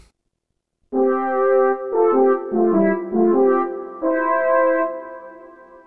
valtorna.wav